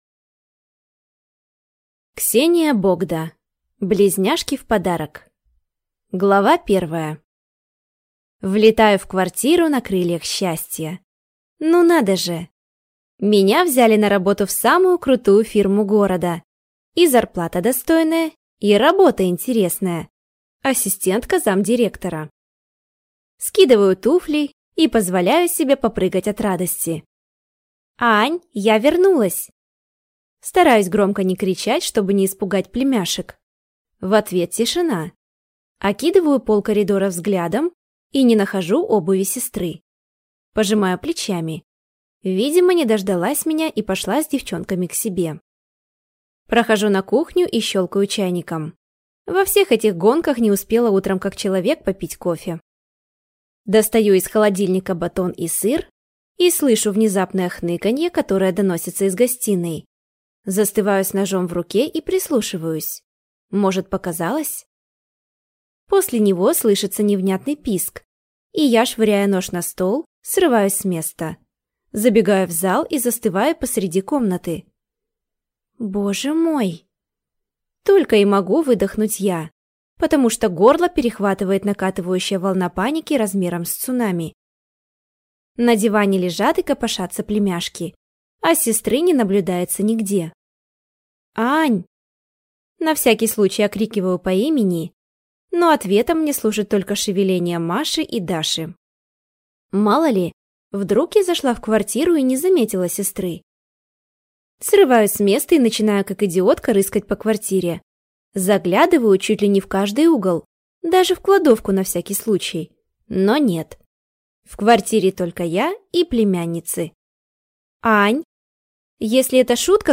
Аудиокнига Близняшки в подарок | Библиотека аудиокниг